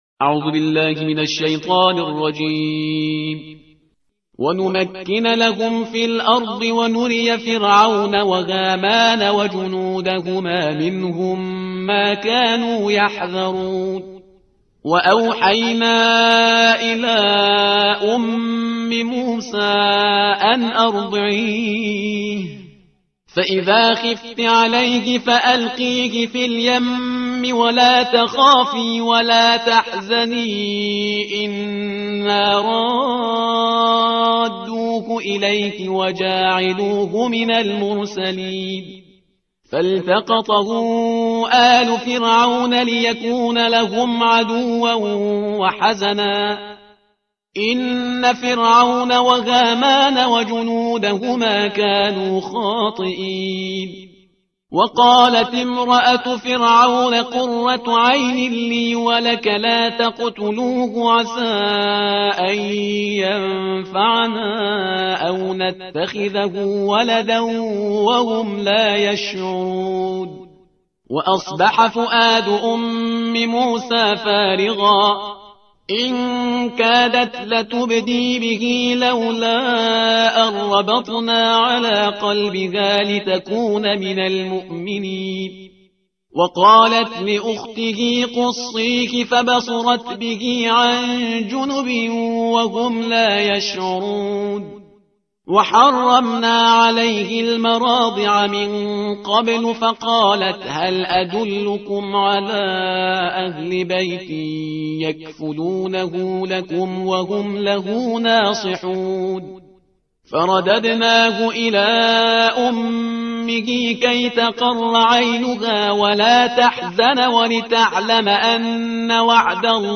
ترتیل صفحه 386 قرآن با صدای شهریار پرهیزگار